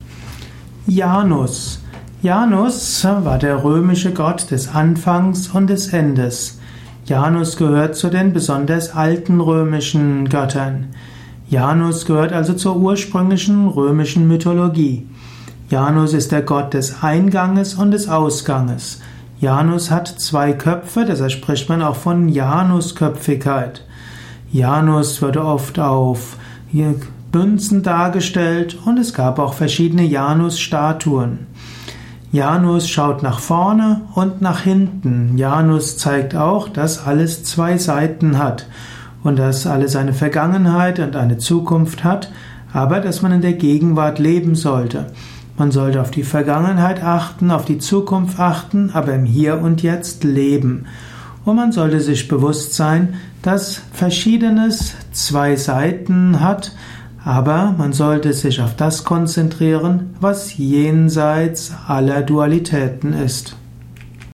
Ein Vortrag über Janus, einem italischen Gott. Ausführungen über die Stellung von Janus in der italischen Mythologie, im italischen Pantheon.
Dies ist die Tonspur eines Videos, zu finden im Yoga Wiki.